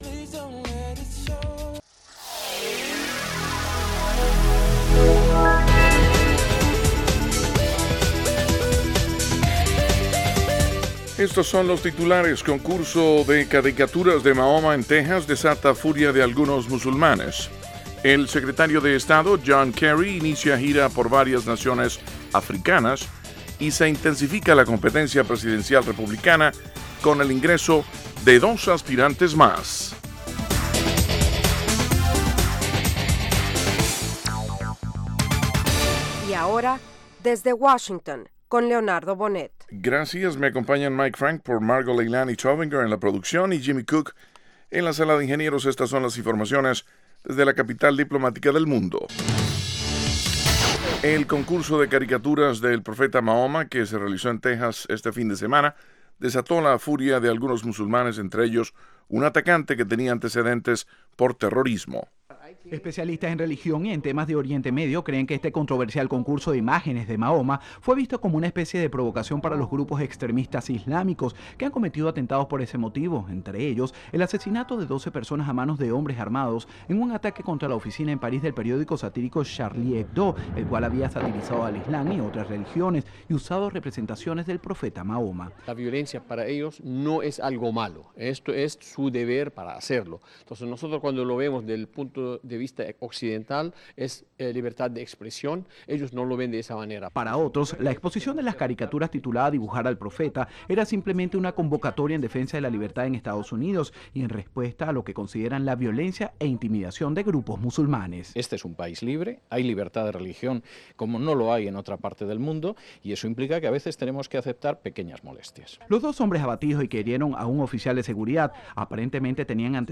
Diez minutos de las noticias más relevantes del día, ocurridas en Estados Unidos y el resto del mundo.